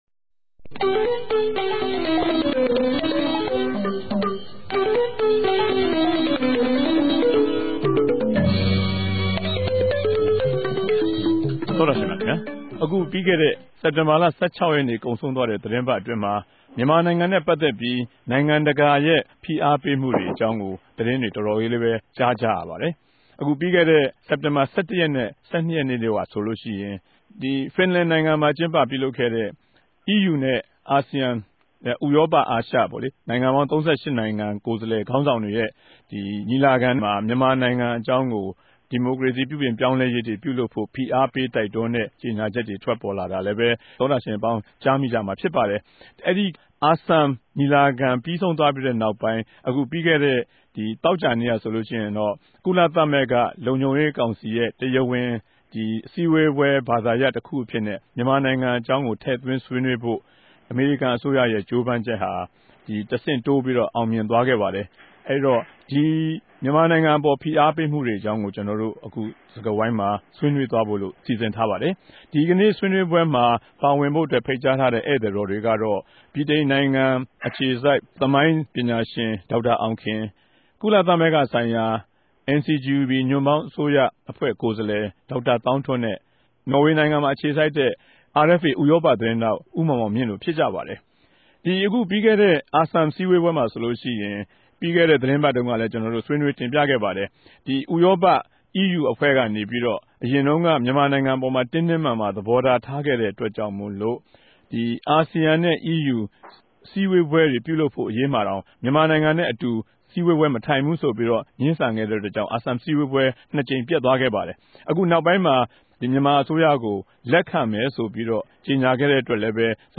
တပတ်အတြင်းသတင်းသုံးသပ်ခဵက် စကားဝိုင်း (၂၀၀၆ စက်တင်ဘာလ ၁၇ရက်)